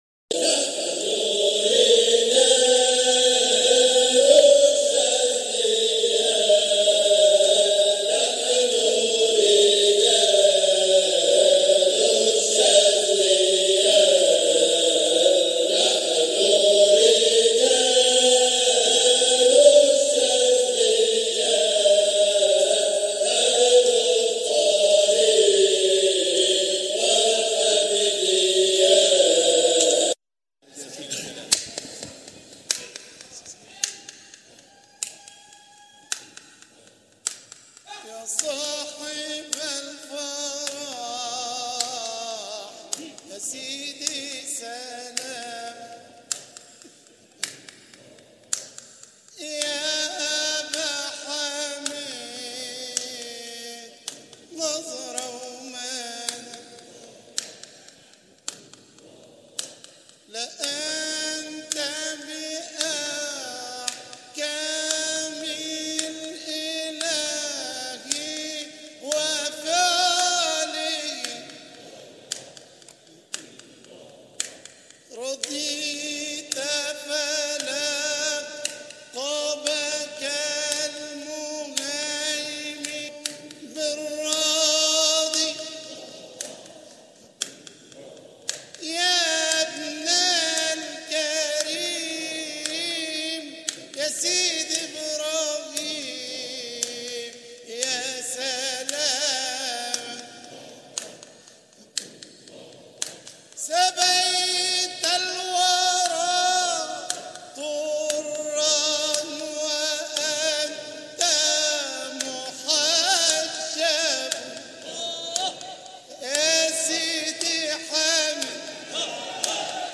مقاطع من احتفالات ابناء الطريقة الحامدية الشاذلية بمناسباتهم
جزء من حلقة ذكر بمسجد مولانا الإمام المؤسس قُدس سره